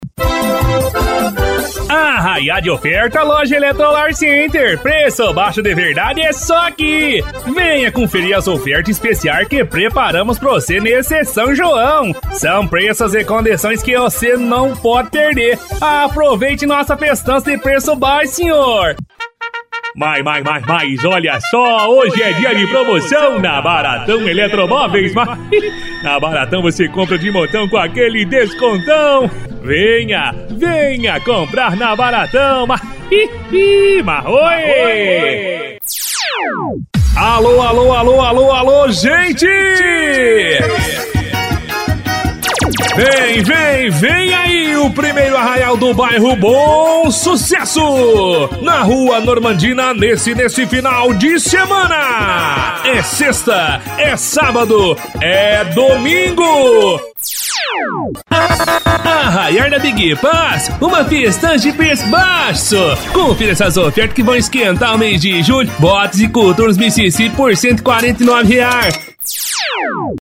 Animada
Caricata